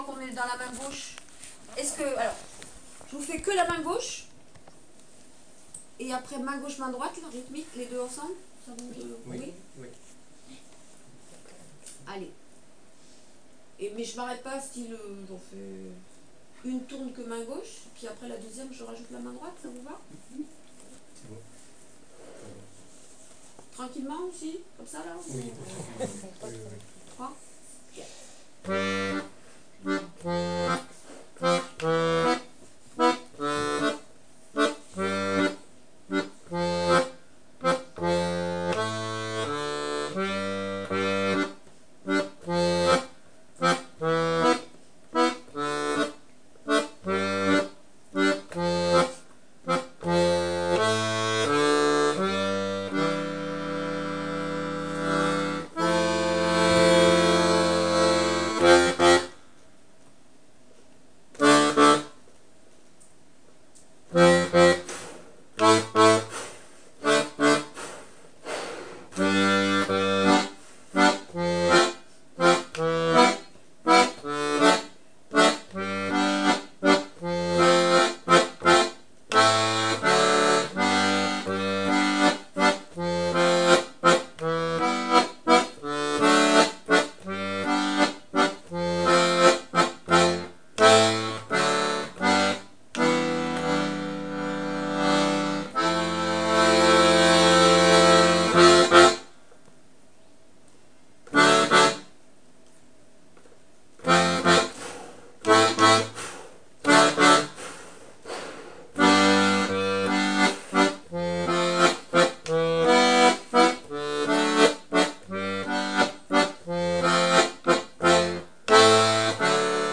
l'atelier d'accordéon diatonique
le contre-chant